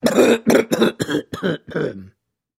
Звуки першения в горле
На этой странице собраны различные звуки першения в горле – от легкого покашливания до навязчивого дискомфорта.